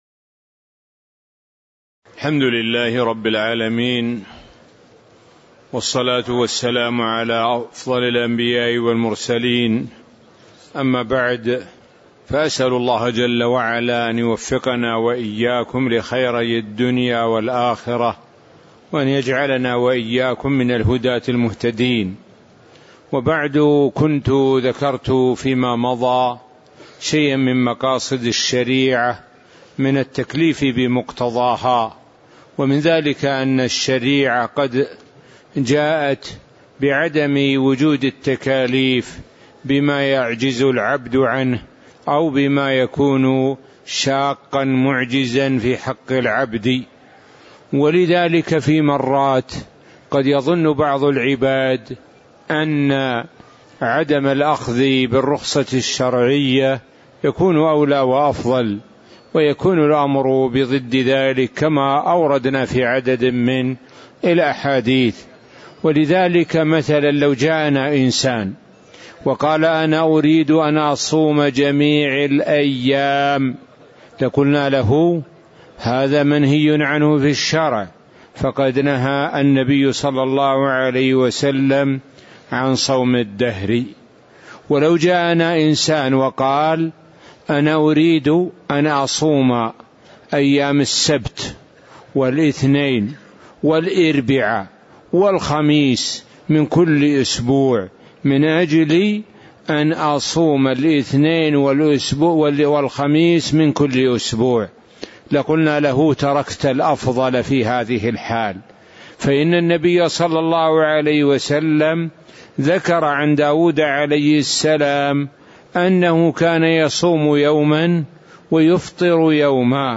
تاريخ النشر ١١ رجب ١٤٣٨ المكان: المسجد النبوي الشيخ: معالي الشيخ د. سعد بن ناصر الشثري معالي الشيخ د. سعد بن ناصر الشثري 02 مقاصد الشريعة في التكليف بمقتضاها